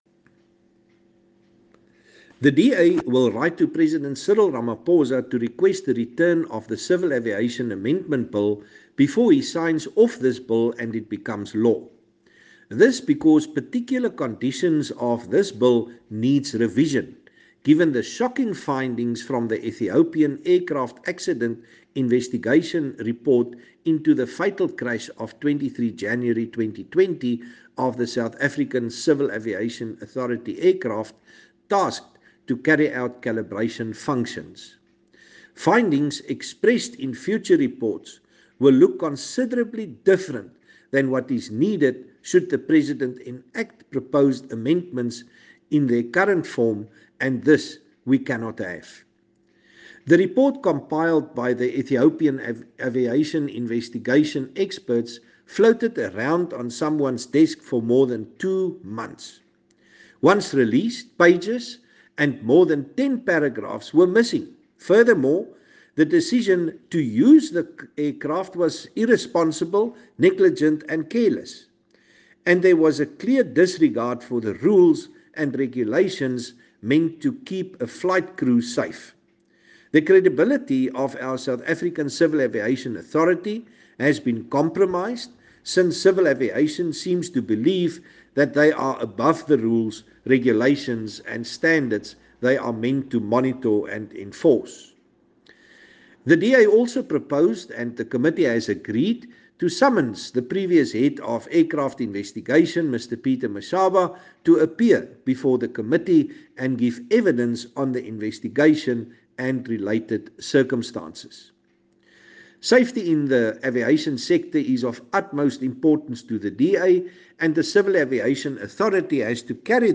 Afrikaans soundbites by Chris Hunsinger MP.
Chris-Hunsinger-English.mp3